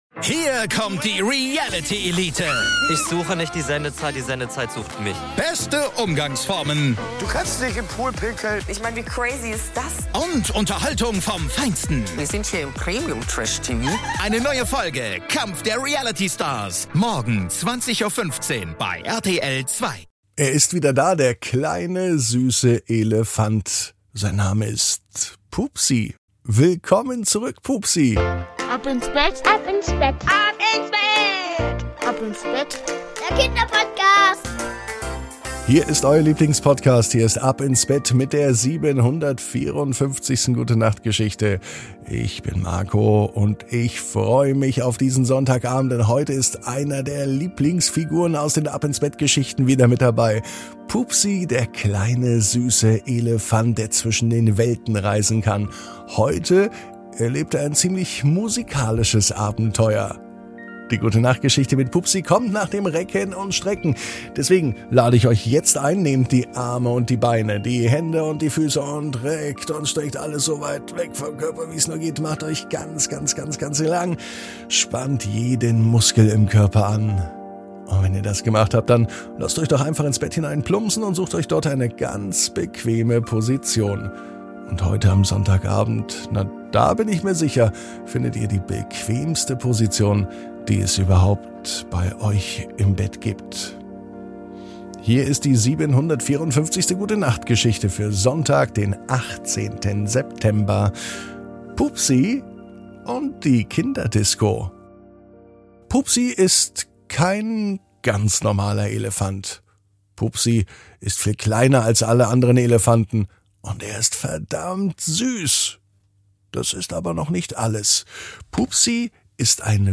#754 Pupsi und die Kinderdisco ~ Ab ins Bett - Die tägliche Gute-Nacht-Geschichte Podcast